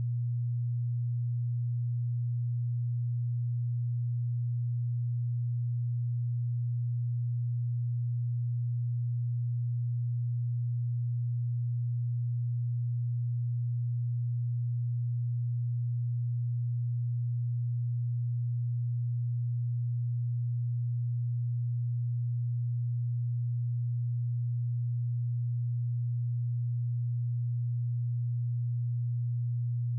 120Hz_-28.dB.wav